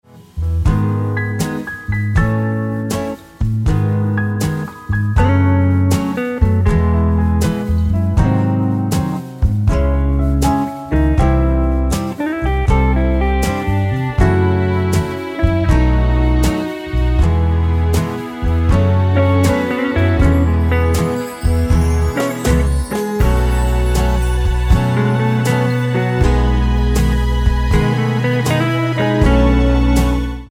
Tonart:E ohne Chor